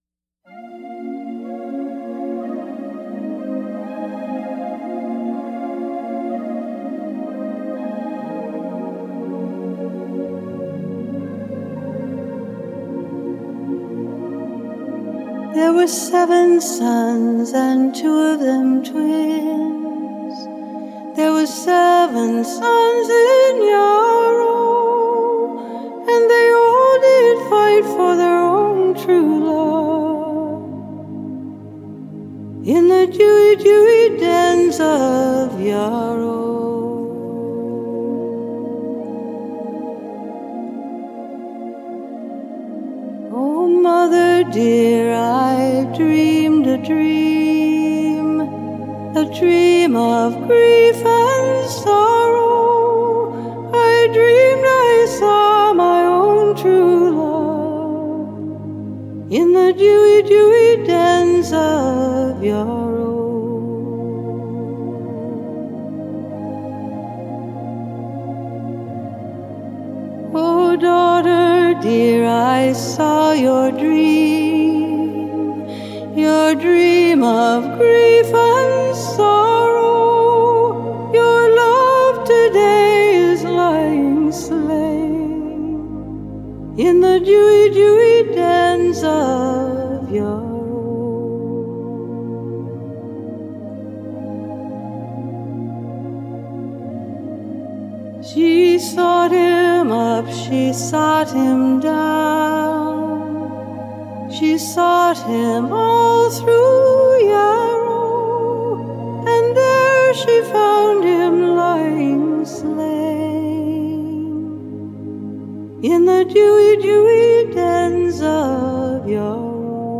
Genre: Pop
愛爾蘭經典民謠